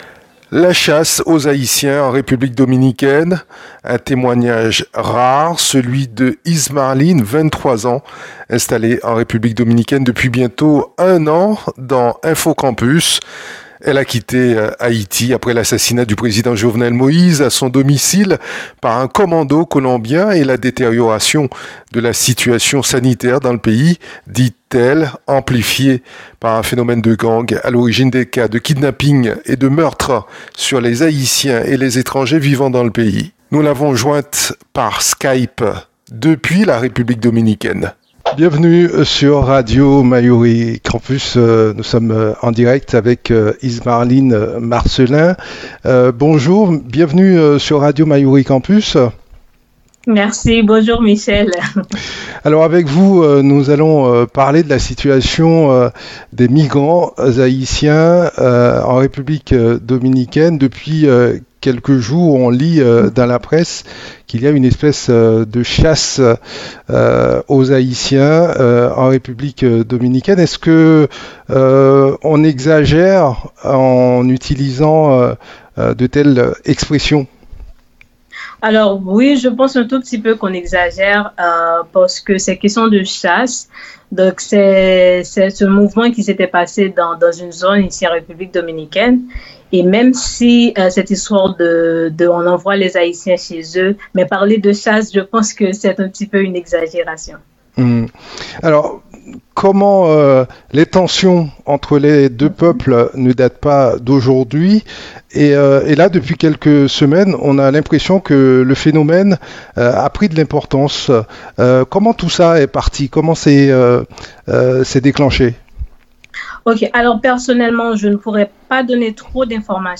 La chasse aux haïtiens en République dominicaine: le témoignage d'une jeune haïtienne sur place.